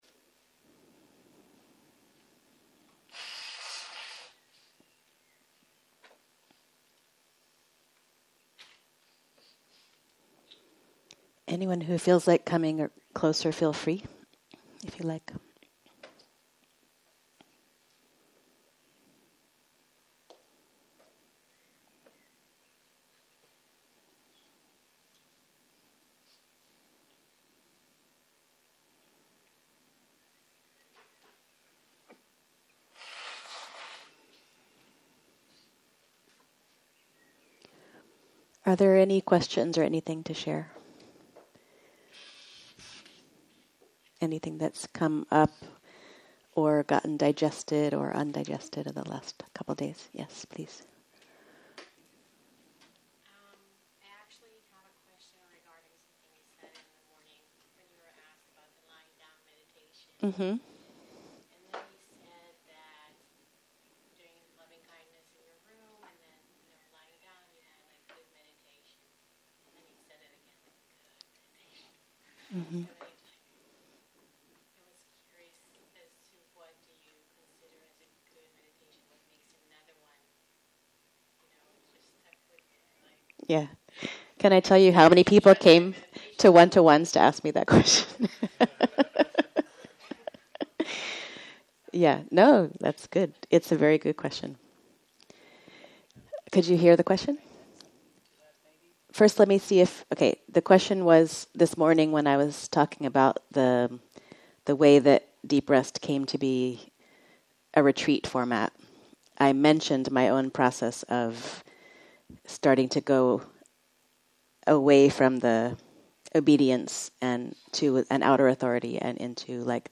05.03.2023 - יום 3 - צהרים - שיחת דהרמה - Getting On With It - הקלטה 5